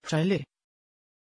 Pronunciation of Charly
pronunciation-charly-sv.mp3